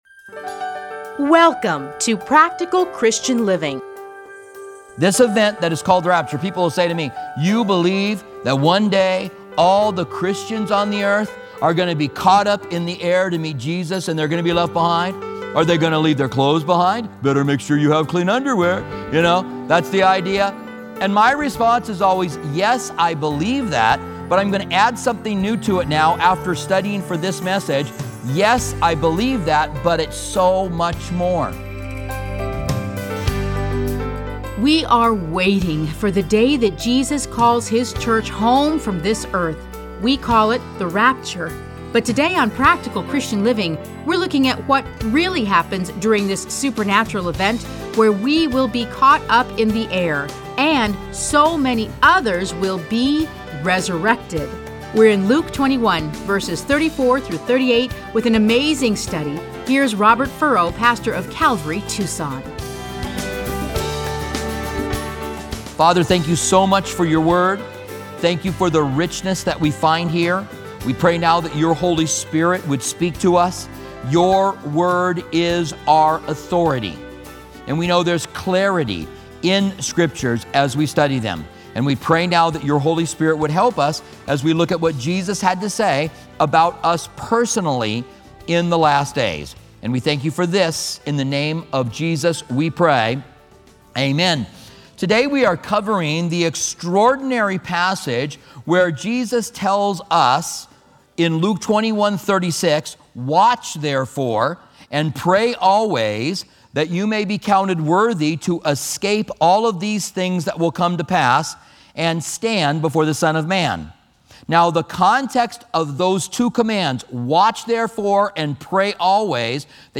Listen to a teaching from Luke 21:34-38.